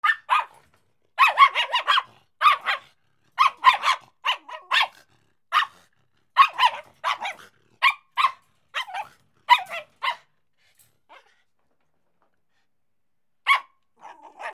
柴犬 鳴き声
/ D｜動物 / D-15 ｜犬